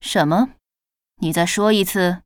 文件 文件历史 文件用途 全域文件用途 Cyrus_tk_01.ogg （Ogg Vorbis声音文件，长度2.3秒，102 kbps，文件大小：28 KB） 源地址:游戏语音 文件历史 点击某个日期/时间查看对应时刻的文件。